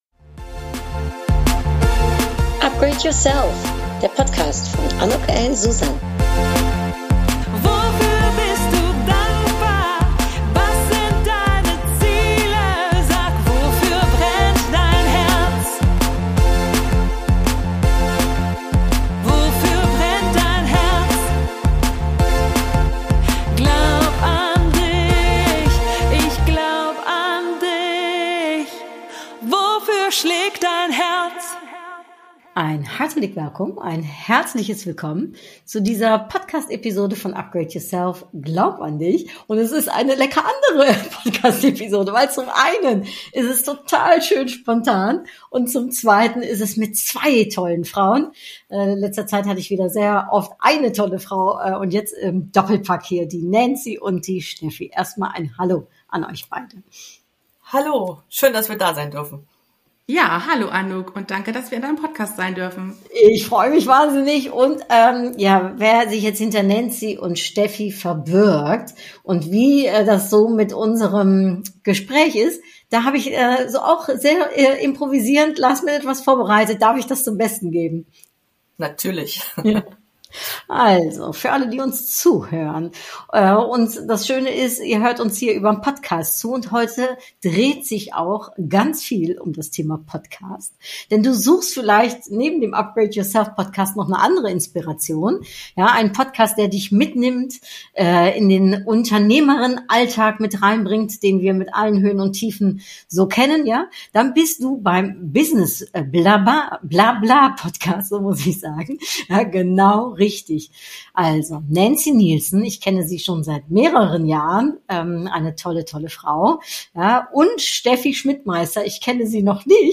Zudem haben wir auch sehr viel zu lachen im Podcast und wird es spannend und weise, als beide ihren Impuls an das jüngere ICH aussprechen…